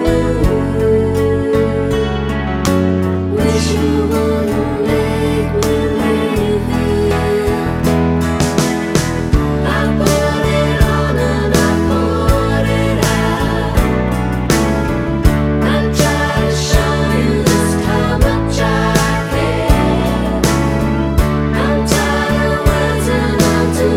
One Semitone Down Rock 5:20 Buy £1.50